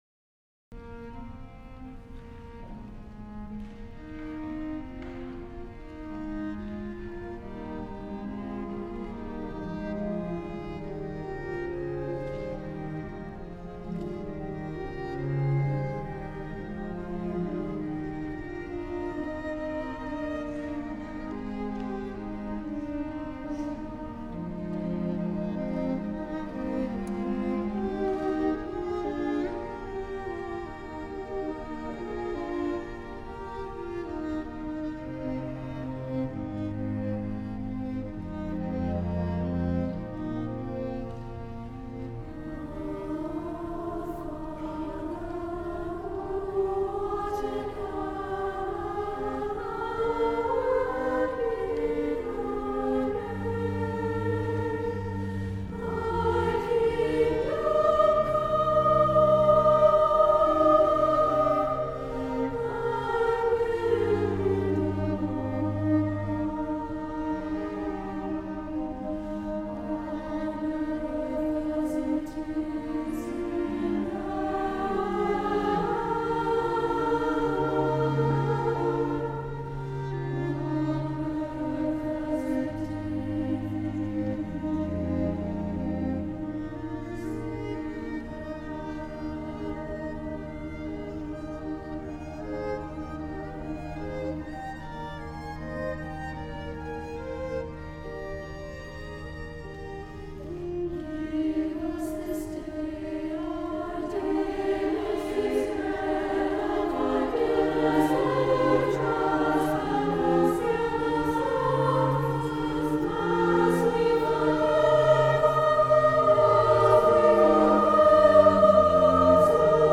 for SSA Chorus and Organ (1988)
This return is the climax of the work, with the choir singing in a 3-part canon.
This is an extended (4-minute) and dramatic setting of The Lord's Prayer.